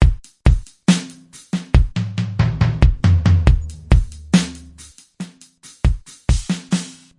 寒冷的和弦
描述：平静的陷阱和弦线索
标签： 139 bpm Trap Loops Synth Loops 1.16 MB wav Key : C
声道立体声